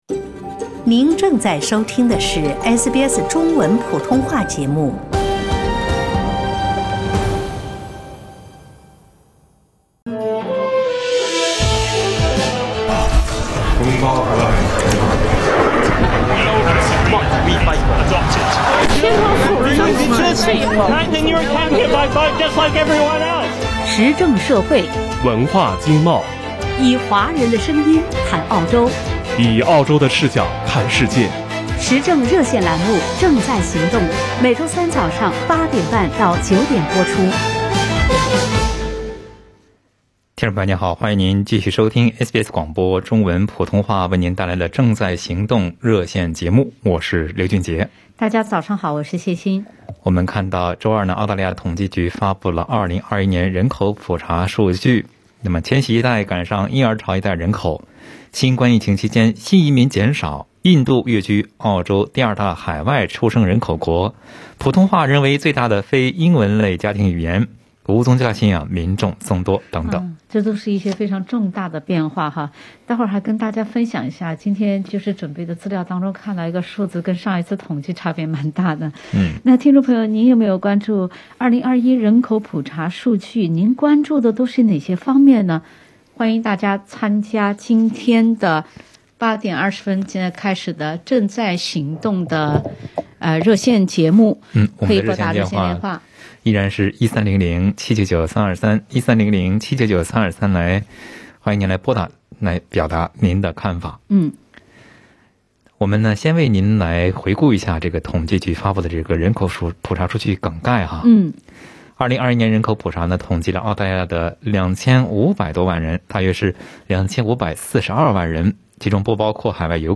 在本期《正在行动》热线节目中，听友们就其关注的人口普查数据表达了各自的看法。